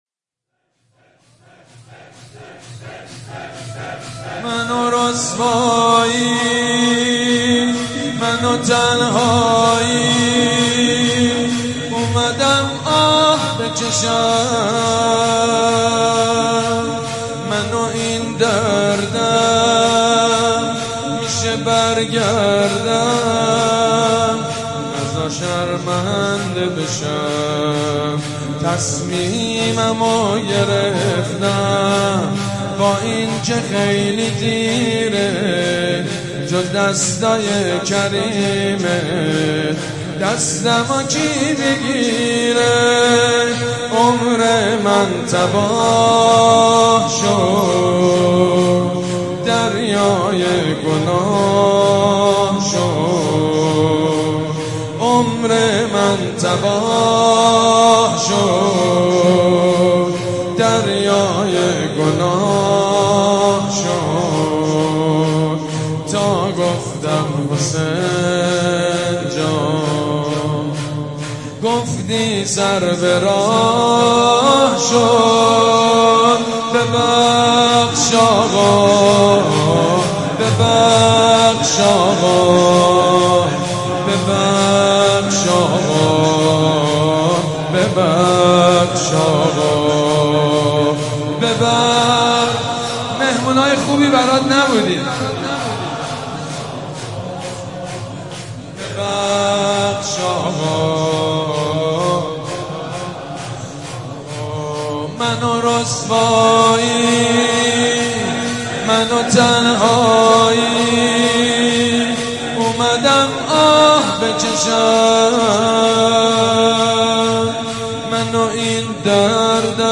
شام غریبان محرم 96 بنی فاطمه
نوحه جديد بنی فاطمه